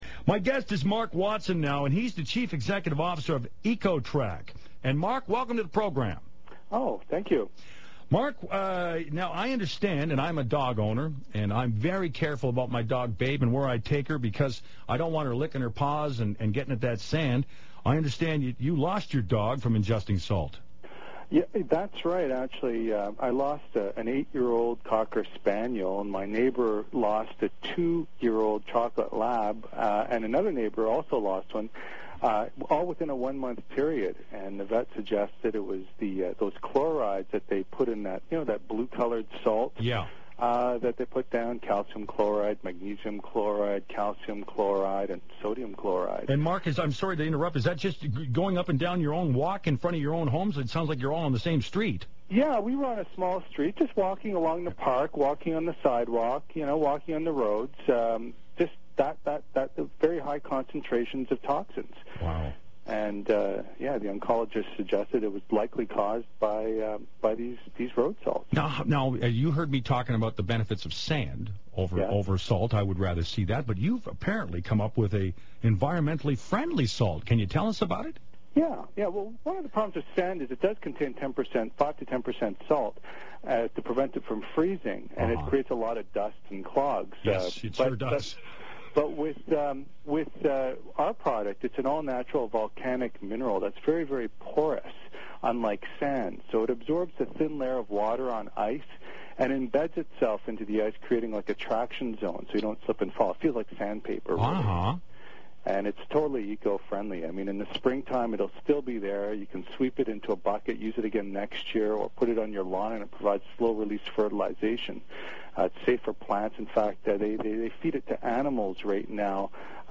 February 23, 2008 Kitchener radio interview
KitchenerRadio_Feb23_08.mp3